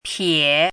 chinese-voice - 汉字语音库
pie3.mp3